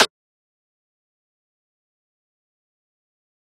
Perc [Juicy].wav